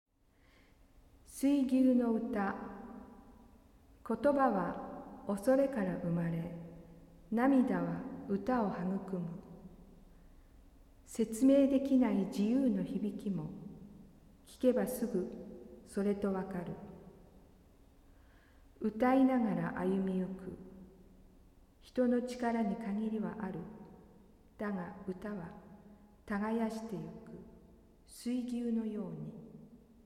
for accordion solo
Poem In Japanese